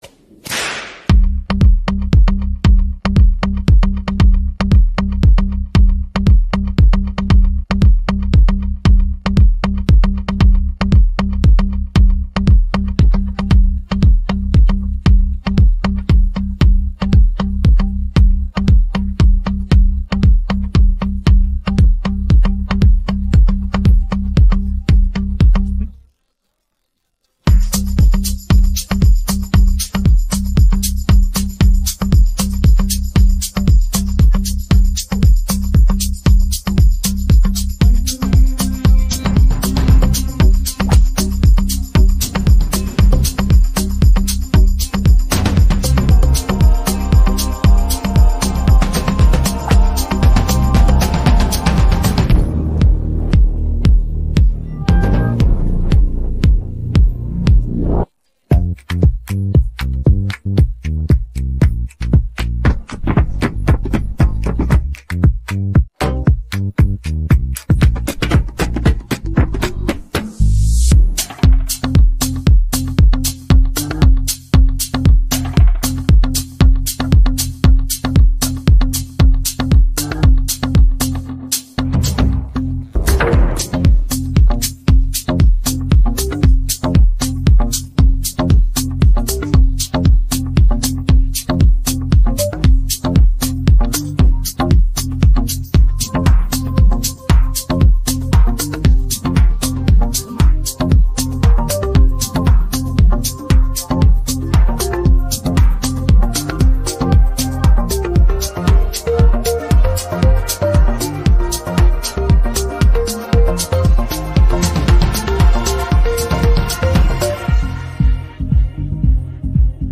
Українські хіти караоке